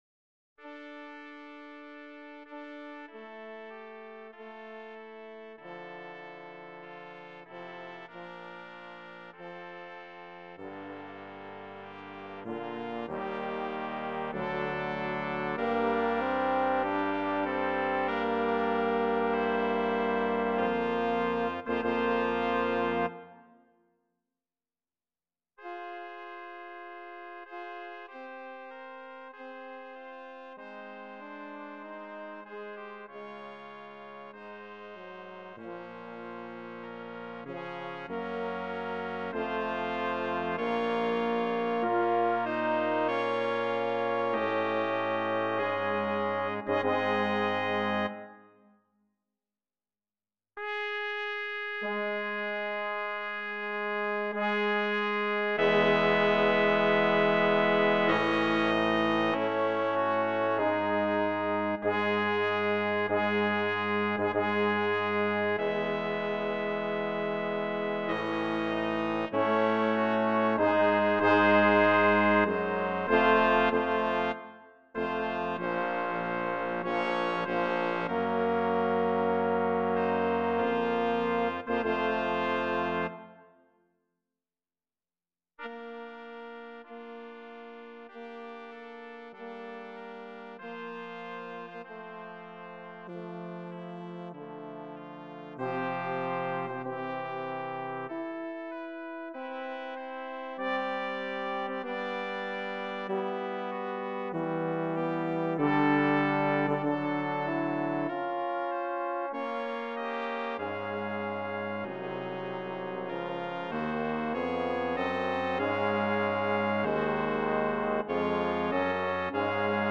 BRASS QUINTET
CHRISTMAS GRADUAL